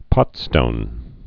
(pŏtstōn)